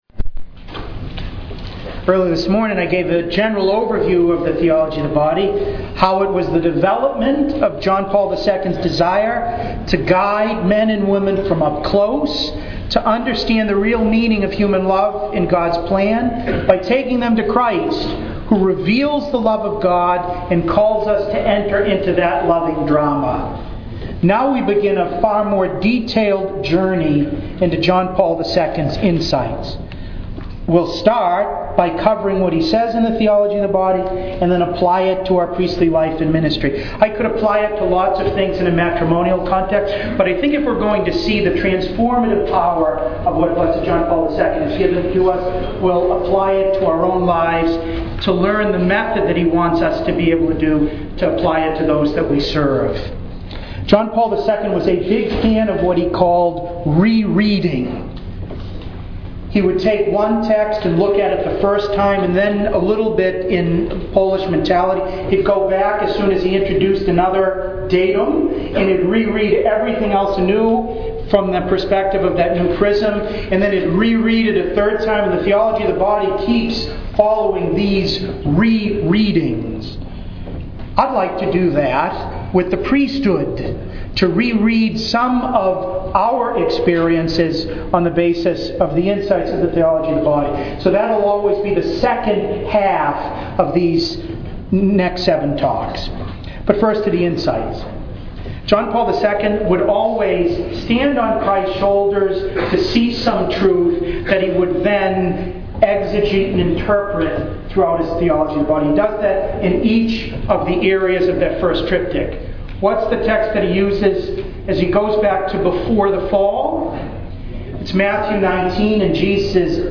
To listen to an audio recording of the talk, please click below.